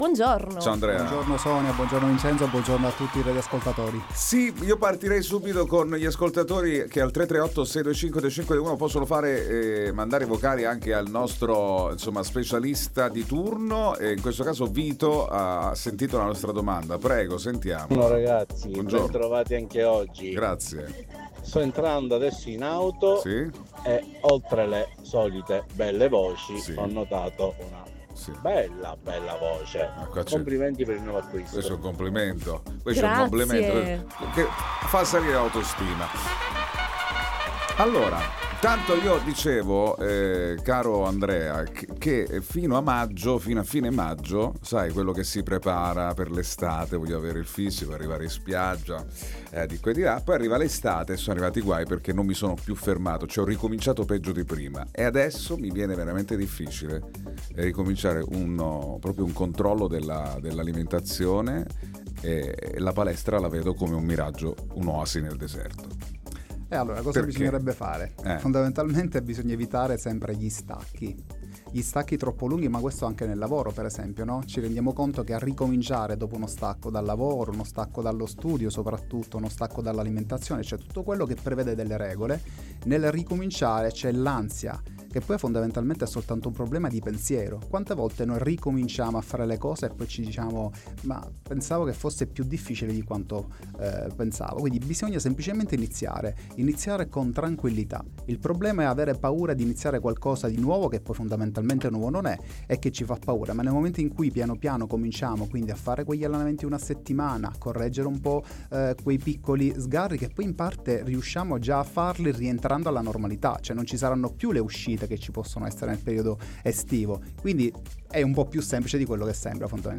All Inclusive Interviste